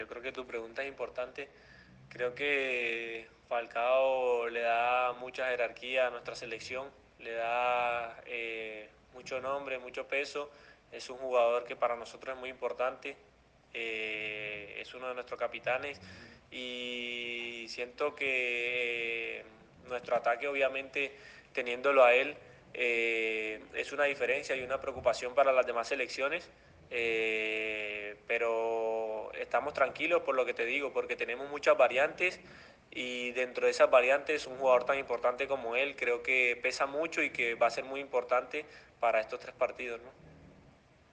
(Rafael Santos Borré en rueda de prensa)